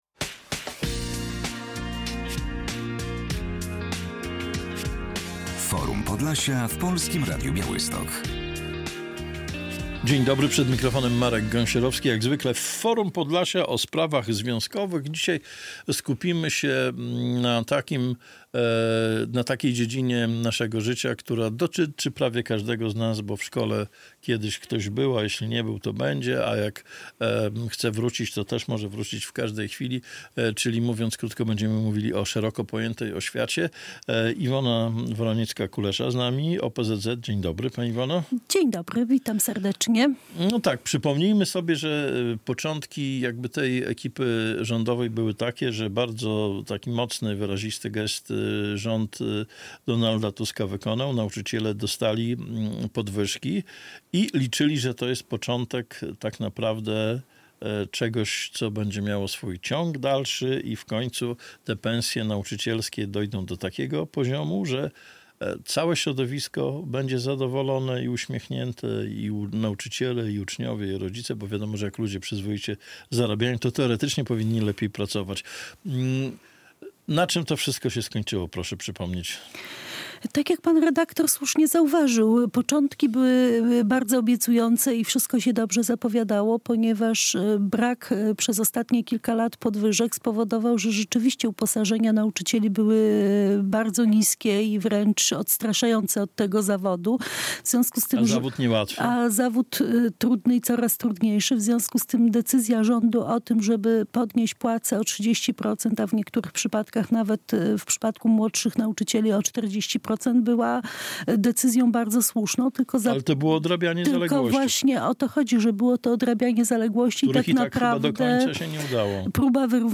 W Forum Podlasia rozmowa o szeroko pojętej oświacie Czy nauczycielom, po podwyżkach, lepiej się pracuje?